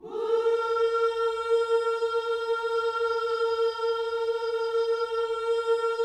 WHOO A#4D.wav